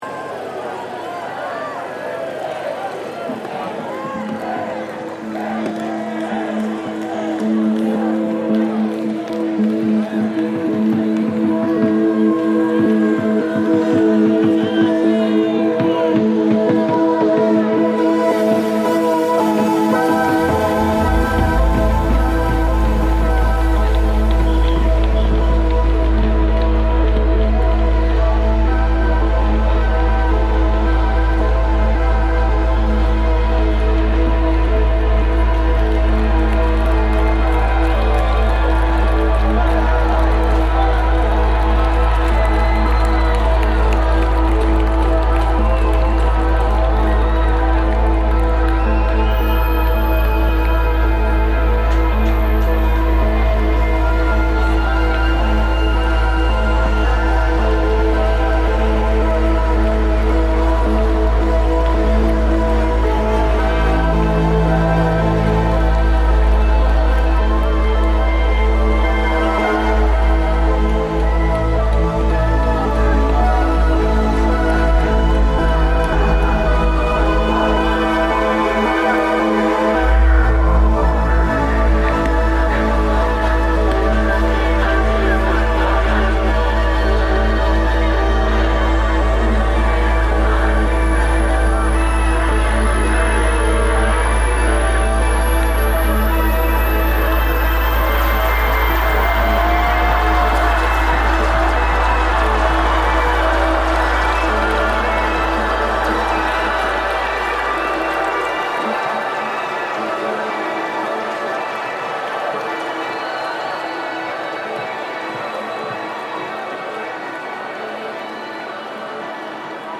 Porto Queima das Fitas festival reimagined